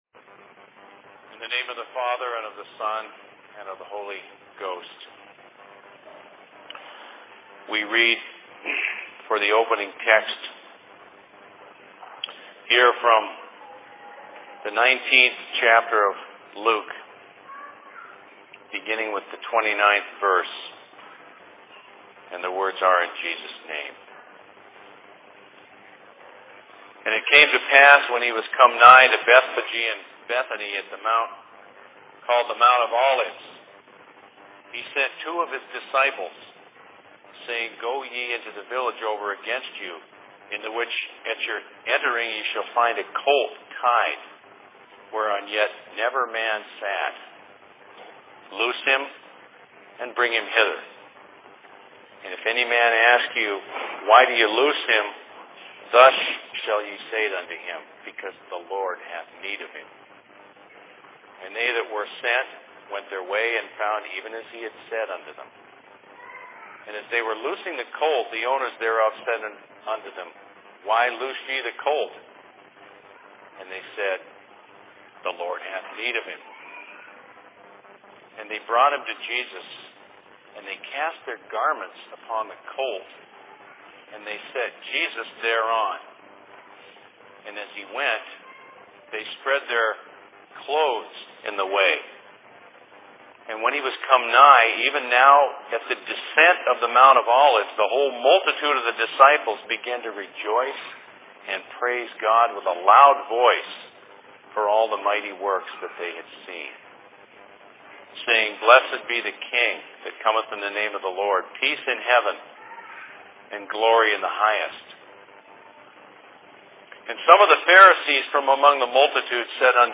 Thanks Giving services/Sermon in Minneapolis 25.11.2005
Location: LLC Minneapolis